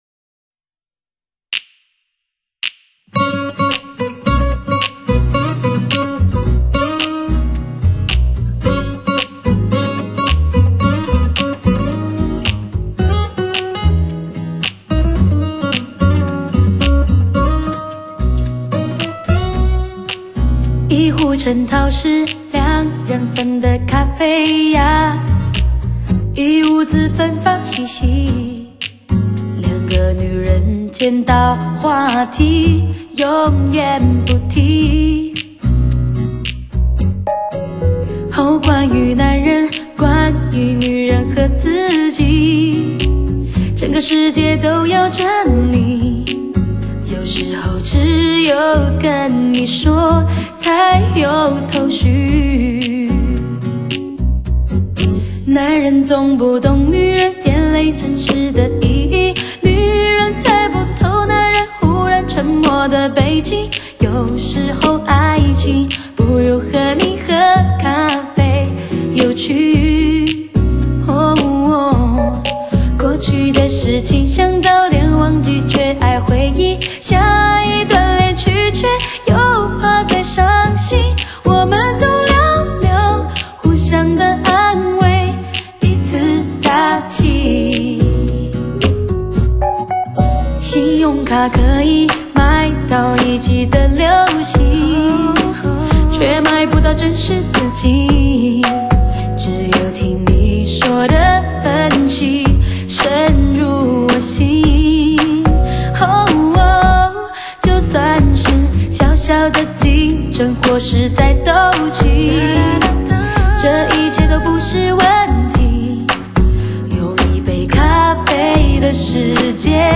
通透 清澈 仿佛一切触手可及 最佳演绎效果的流行发烧天碟